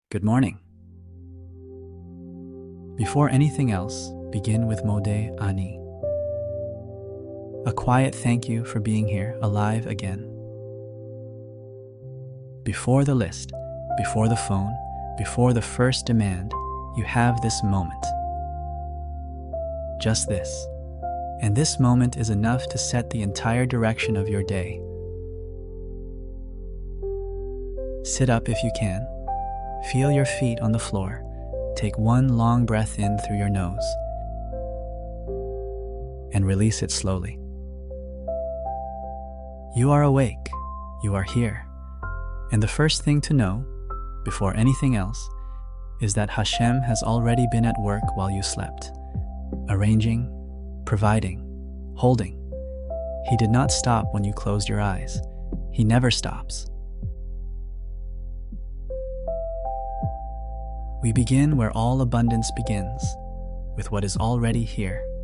No hype. No pressure. Just a calm, daily return to Hashem.